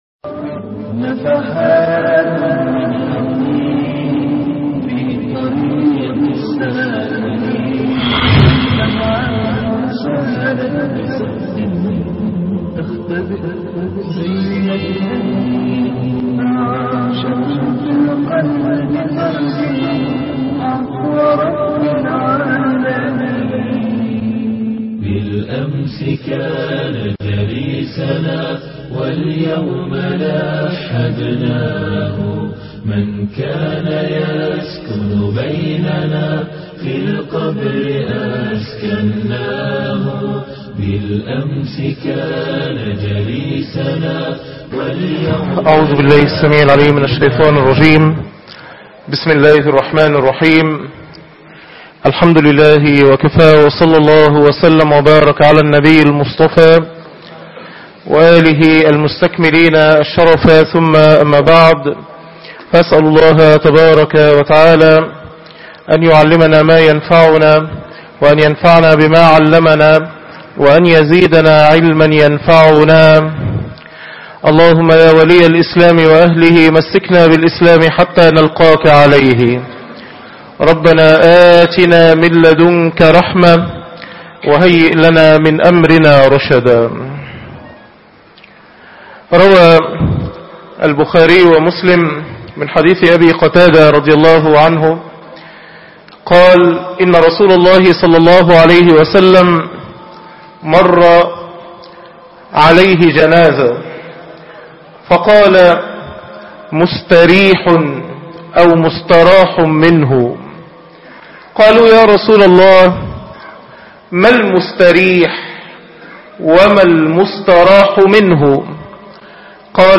موعظة مودع- خطب الجمعة